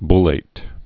(blāt, bŭl-)